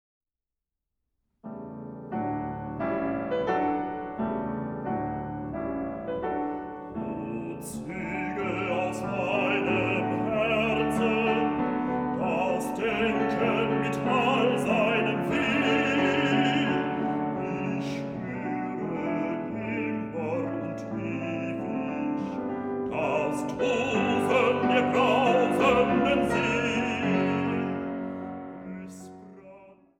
Bassbariton
Klavier